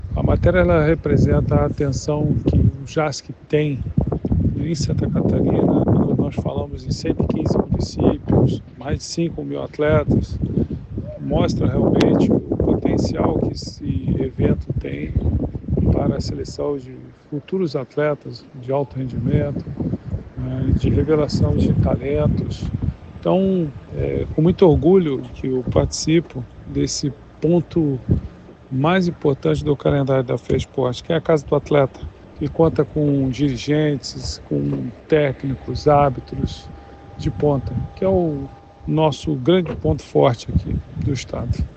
O evento faz parte da história do esporte catarinense, como ressalta o presidente da Fesporte, Freibergue Nascimento: